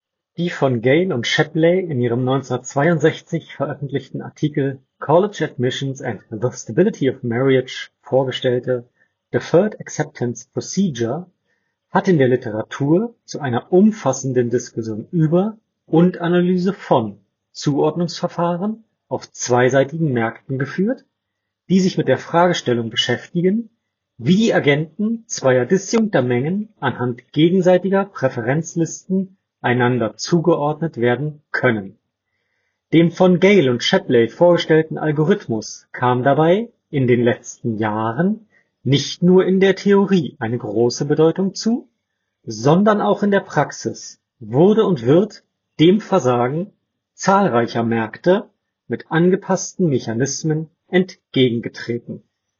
Huawei FreeArc – Mikrofonqualität